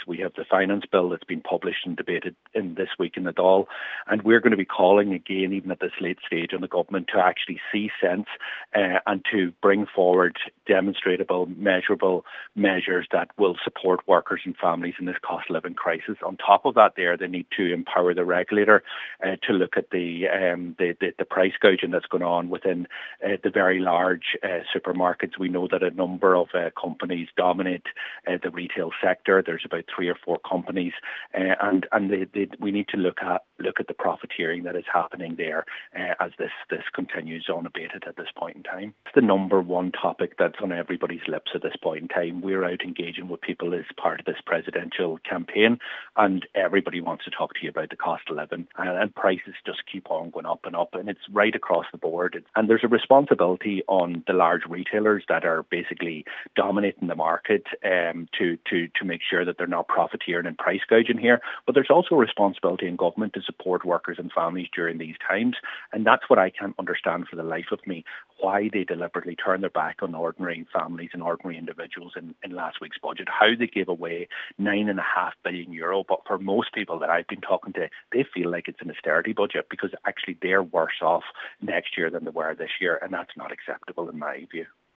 He says regulators must be given the powers they need to investigate price gouging by large supermarkets: